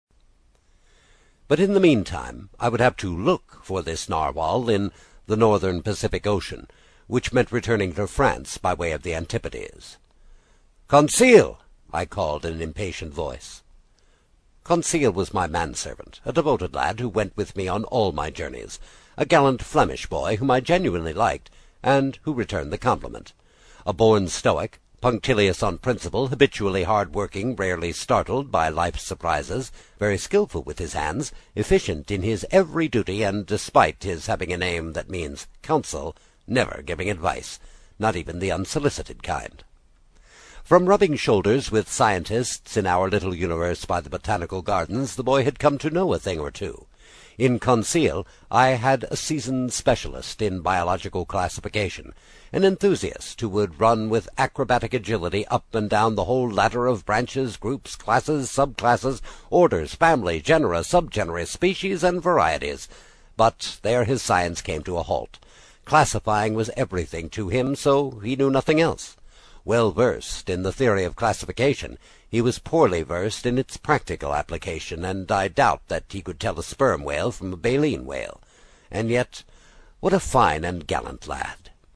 在线英语听力室英语听书《海底两万里》第24期 第3章 随您先生的便(2)的听力文件下载,《海底两万里》中英双语有声读物附MP3下载